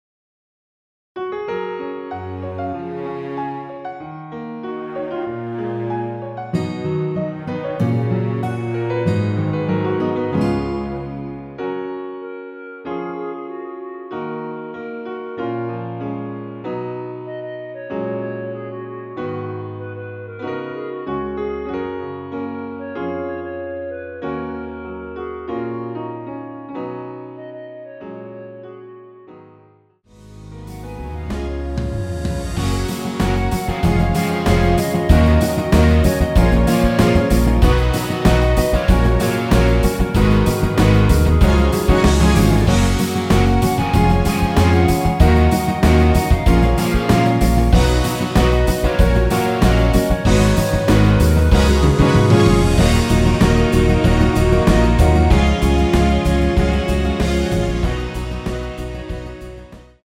원키 멜로디 포함된 MR입니다.
F#
앞부분30초, 뒷부분30초씩 편집해서 올려 드리고 있습니다.